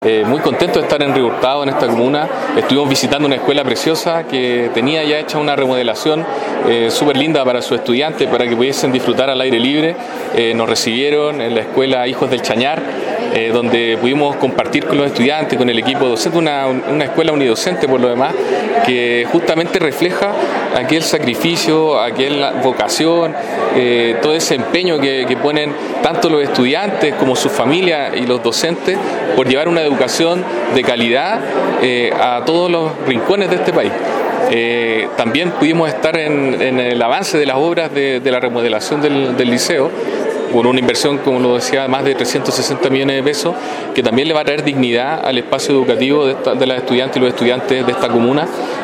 El Delegado Presidencial Provincia de Limarí, Galo Luna Penna expresó su satisfacción de estar en Río Hurtado señalando que
4.-Galo-Luna-Penna-Delegado-Presidencial-Provincia-de-Limari_1.mp3